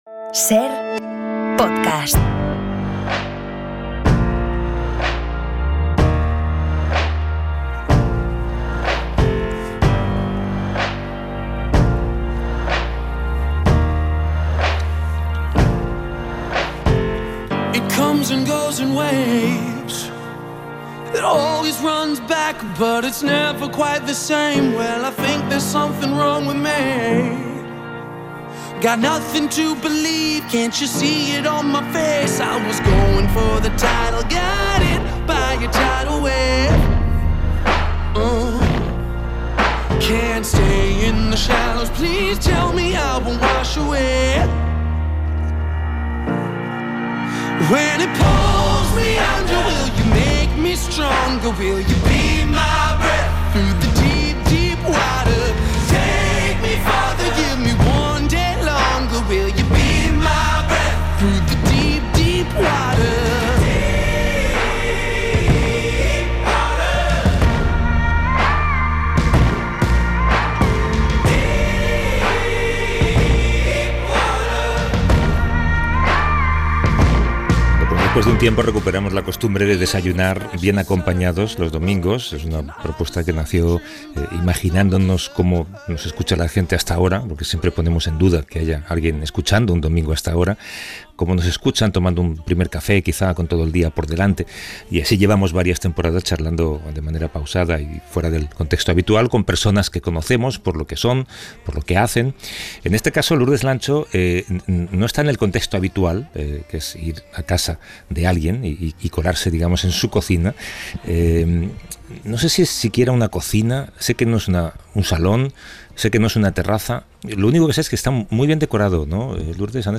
Hoy no desayunamos porque en un museo no se puede, pero la conversación con esta cordobesa que ha vivido en medio mundo es muy nutritiva.